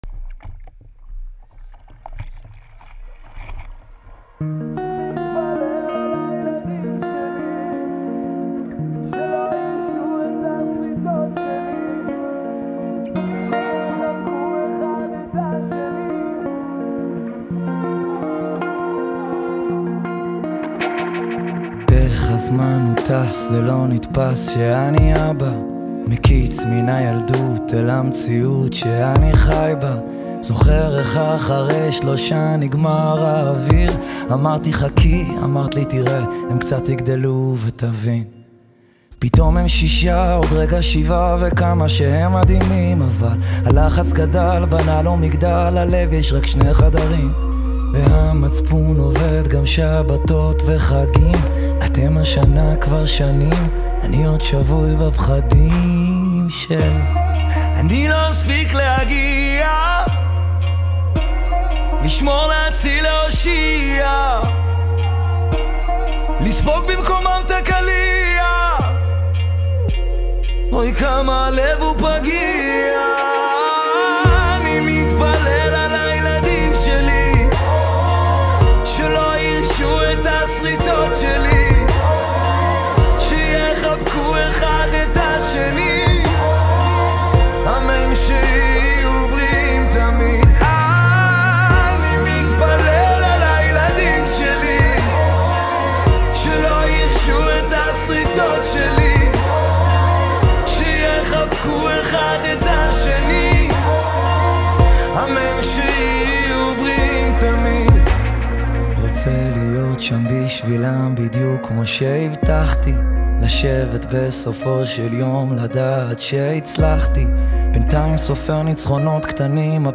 גיטרות
סופרן דיסטורשן
פסנתר, קלידים ופרוגרמינג